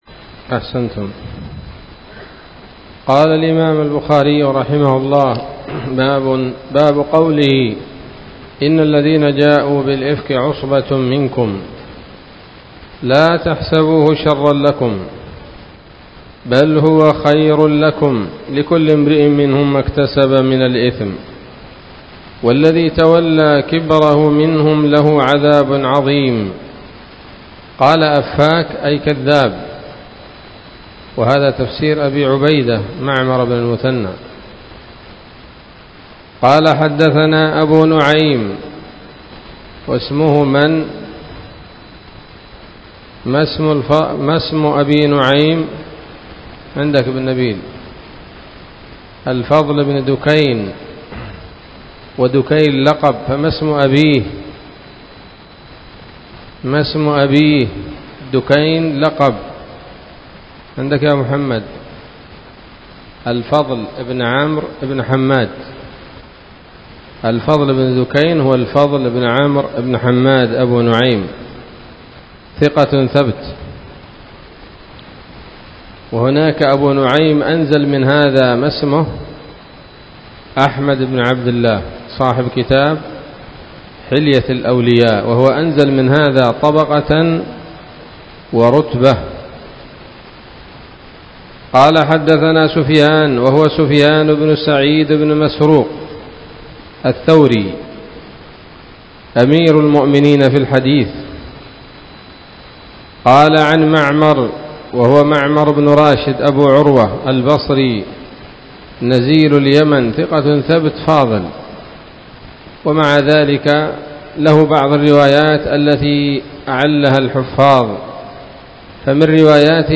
الدرس الثاني والثمانون بعد المائة من كتاب التفسير من صحيح الإمام البخاري